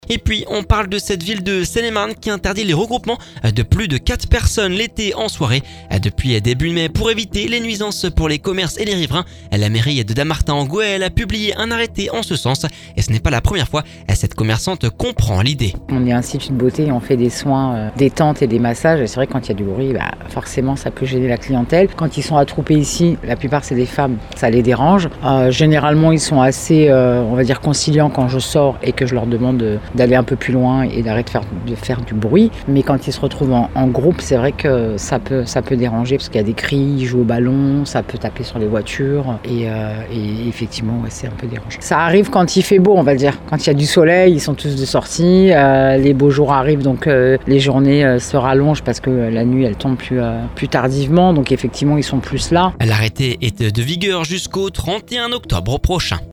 Cette commerçante comprend l'idée…